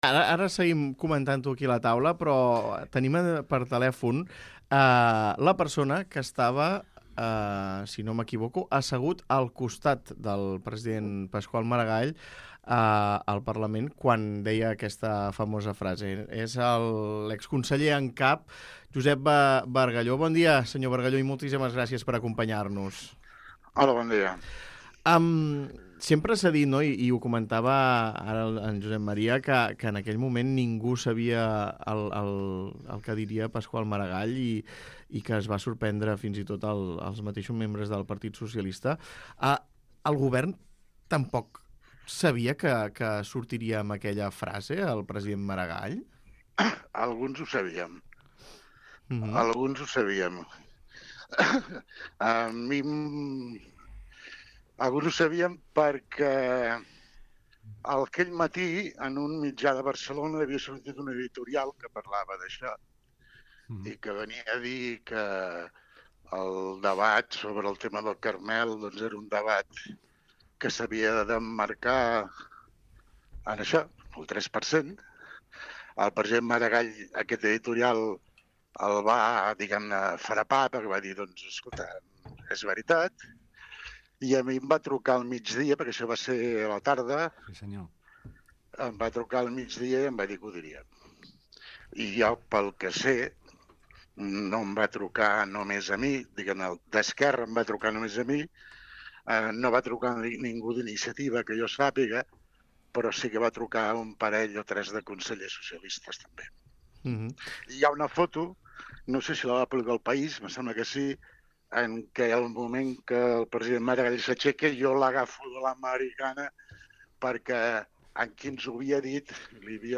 Escolta les entrevistes a Josep Bargallo, exconseller en cap del primer govern tripartit, i Joan Lopez Alegre, exdiputat del PP